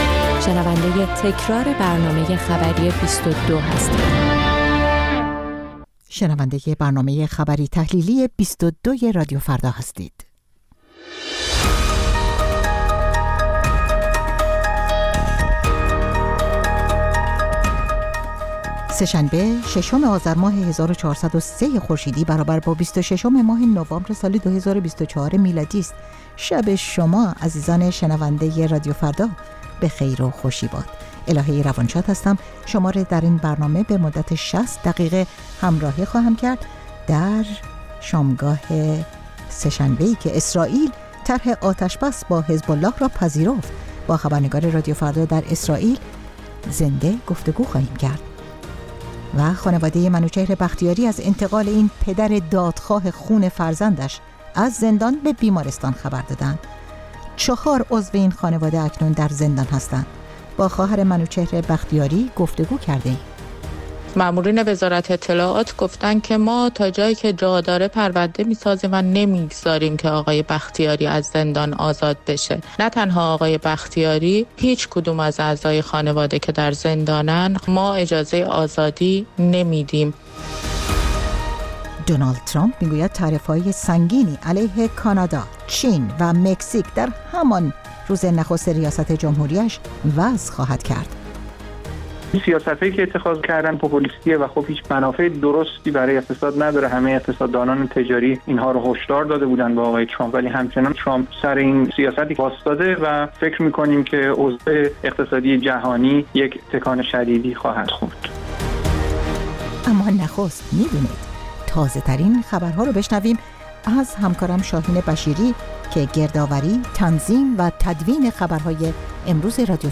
بازپخش برنامه خبری ۲۲